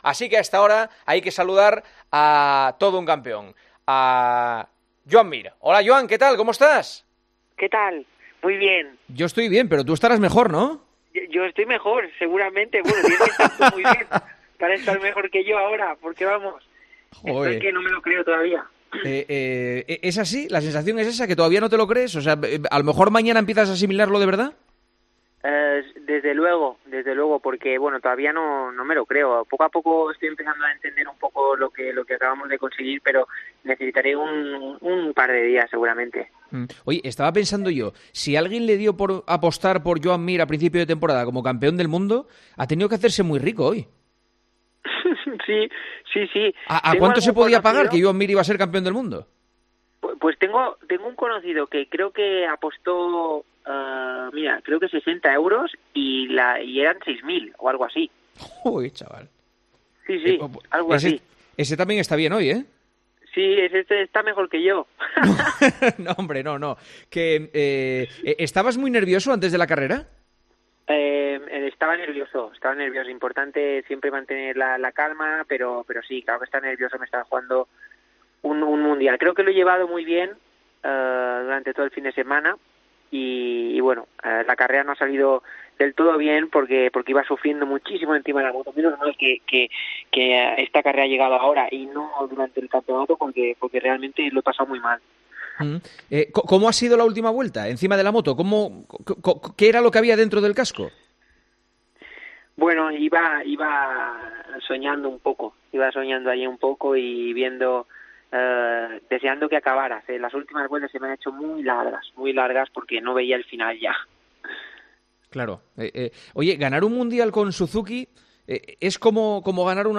El nuevo campeón del Mundo de MotoGP, Joan Mir, acabó este domingo histórico compartiendo su alegría con los oyentes de Tiempo de Juego.